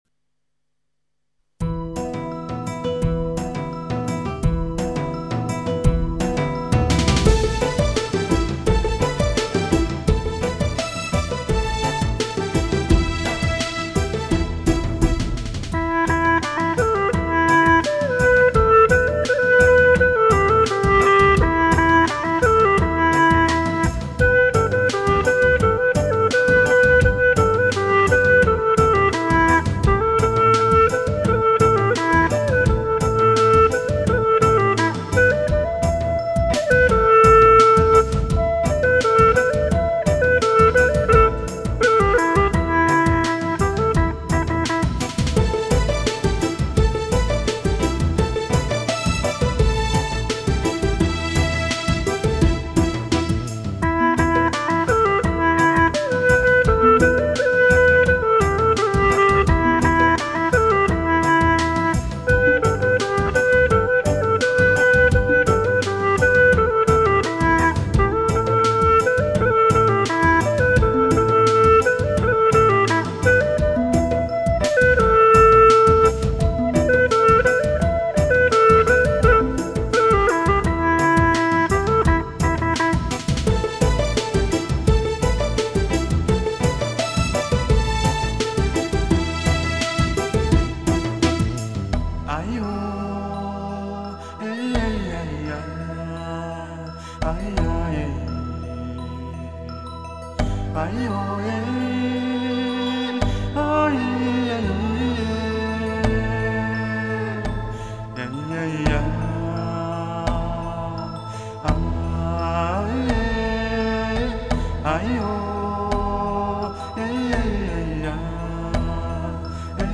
标题: 葫芦丝名曲系列《阿佤人民唱新歌》欣赏G [打印本页]
比较新的伴奏,声音很好听,谢谢.